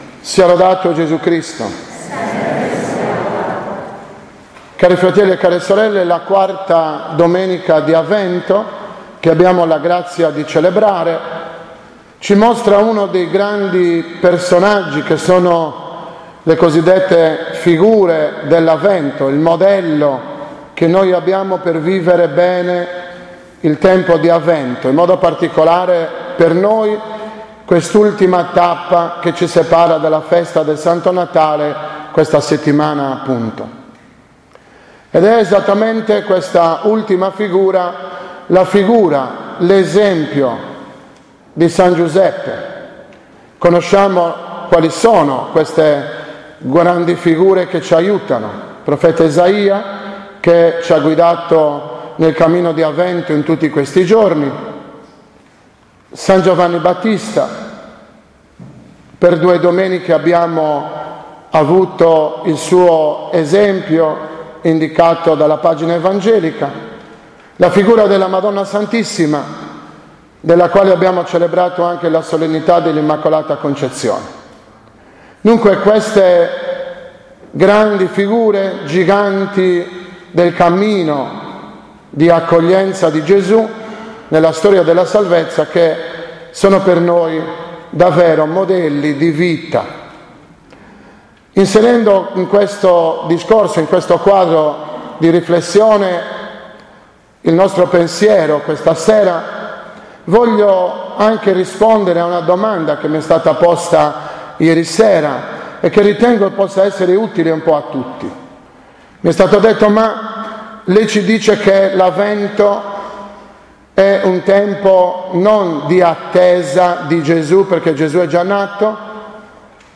18.12.2016 – OMELIA DELLA IV DOMENICA DI AVVENTO